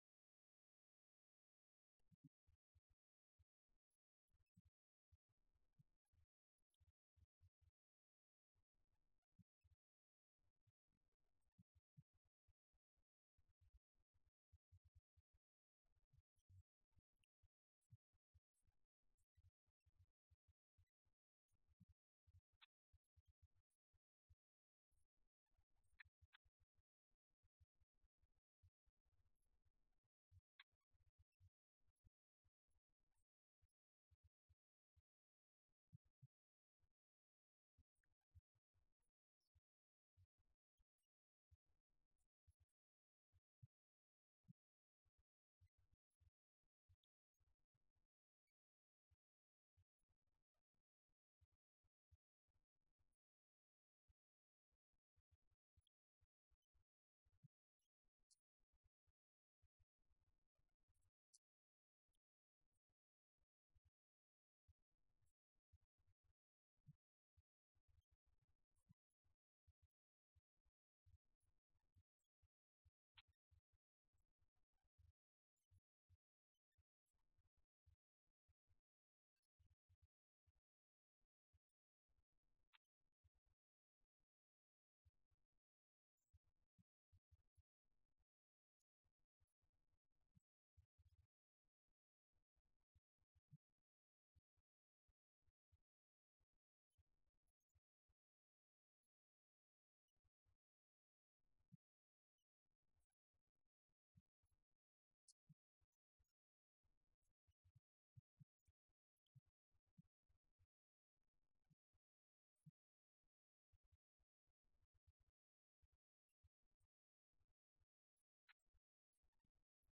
Event: 17th Annual Schertz Lectures
lecture